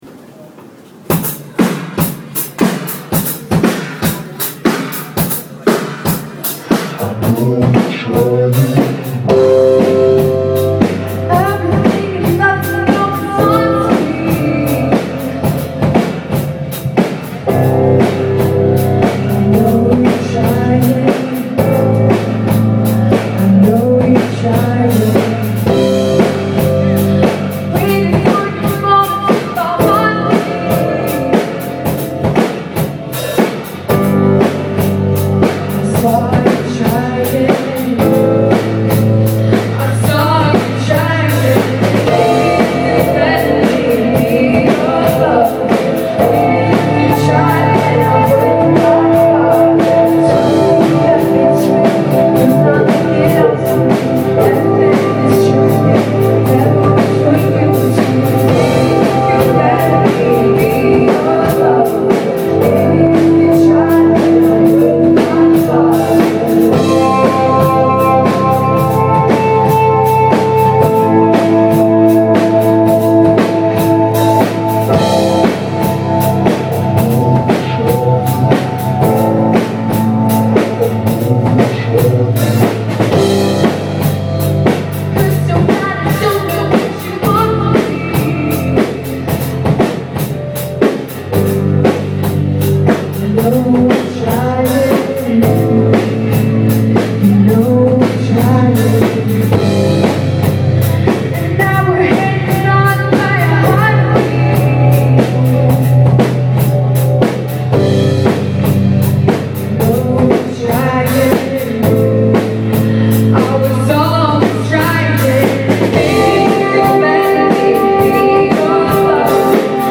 Crisp pop tunes stood out because of such powerful vocals.
+Live at the Brooklyn Vegan showcase